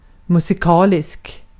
Ääntäminen
IPA : /ˈmju.zɪ.kəl/